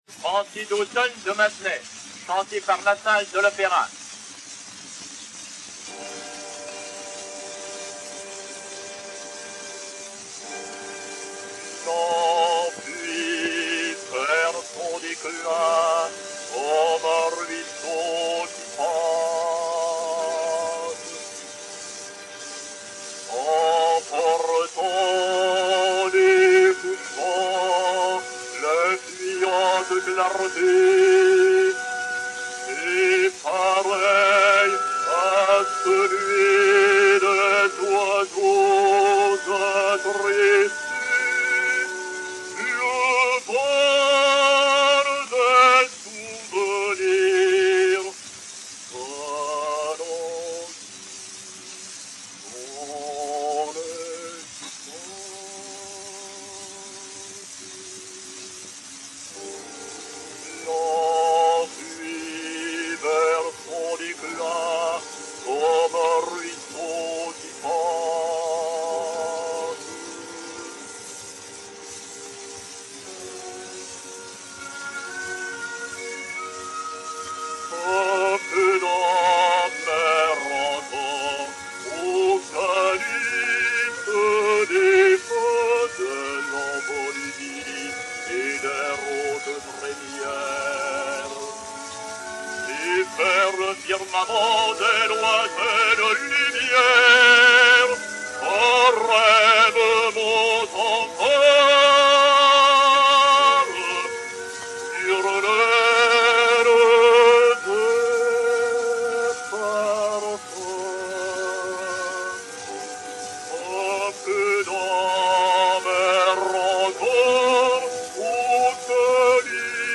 Jean-Louis Lassalle, baryton, avec piano